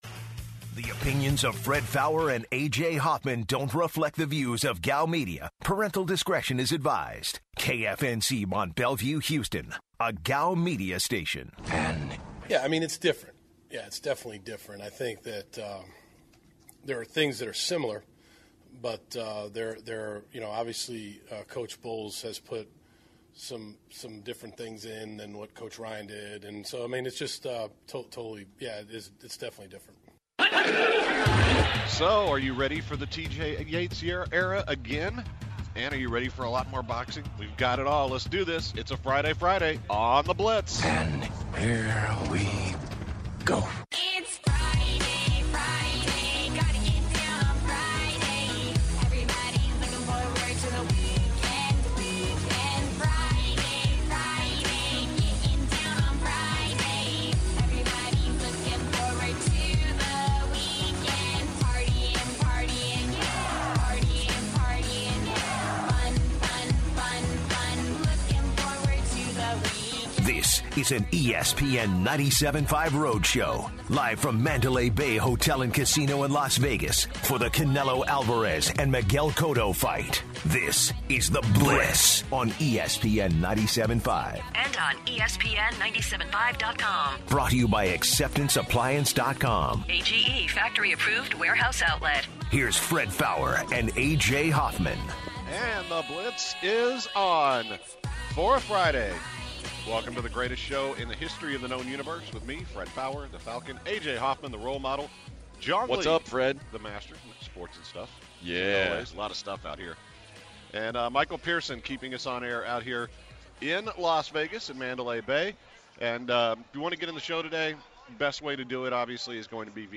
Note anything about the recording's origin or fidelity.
Live from the Mandalay Bay Hotel and Casino in Las Vegas, Nevada. The guys start the show off by talking about the boxing fight tomorrow Cotto vs. Canelo. What TJ Yates can do against the Jets defense?